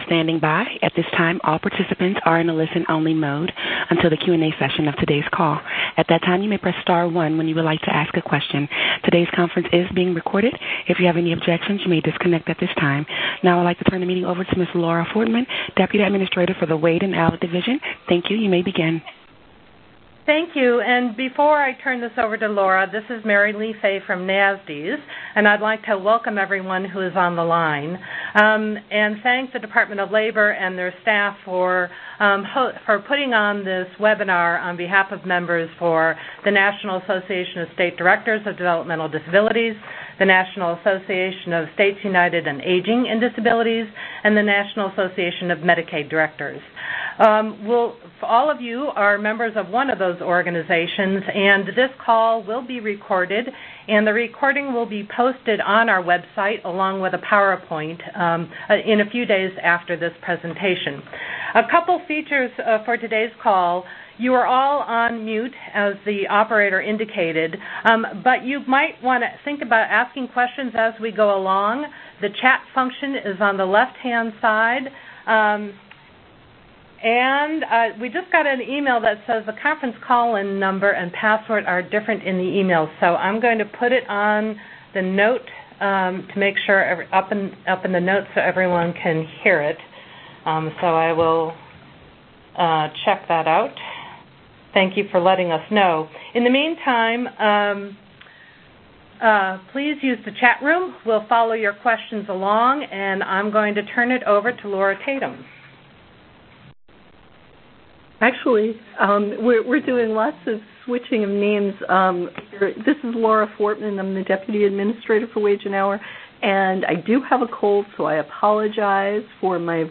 On May 8, NASDDDS, NAMD, and ADvancing States jointly hosted a webinar and conference call with the Department of Labor to discuss the Fair Labor Standards Act (FLSA) Home Care Final Rule and to provide guidance on shared living arrangements.
May 8th Home Care Webinar.mp3